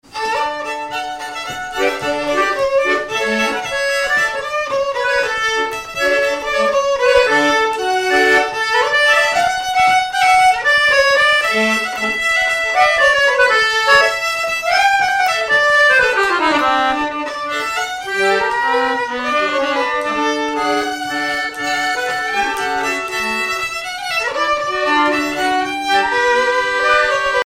Miquelon-Langlade
danse : marche
violon
Pièce musicale inédite